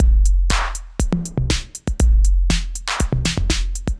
Index of /musicradar/80s-heat-samples/120bpm